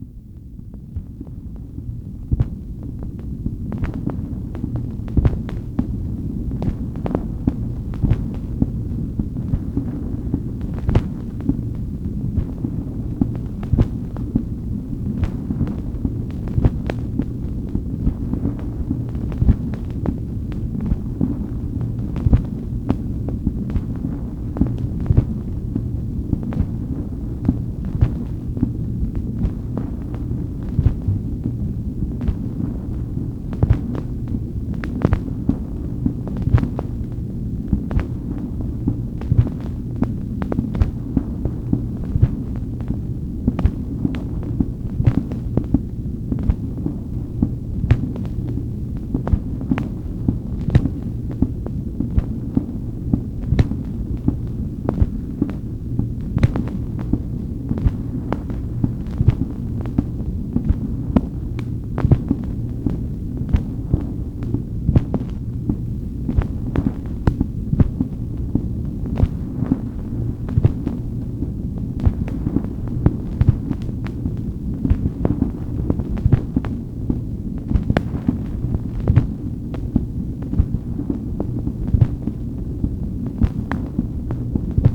MACHINE NOISE, February 7, 1964